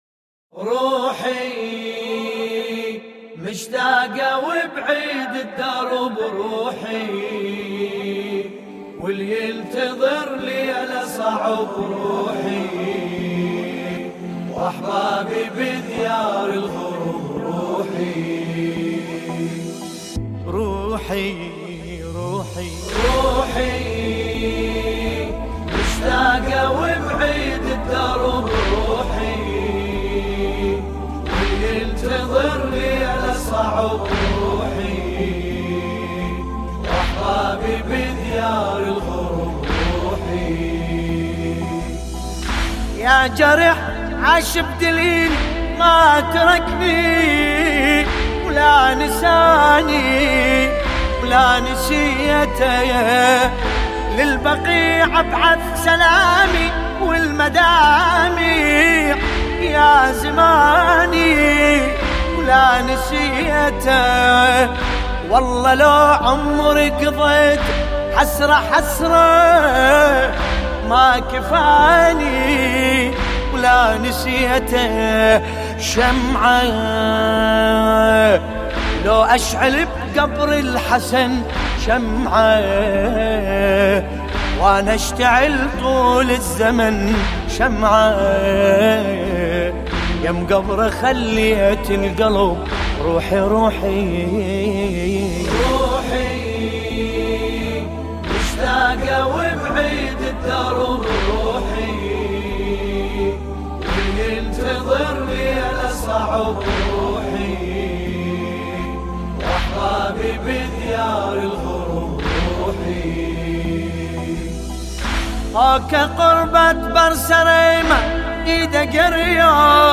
مداحی ملا باسم به مناسبت 8 شوال سالروز تخریب بقیع